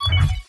Computer Calculation Notificaiton 3.wav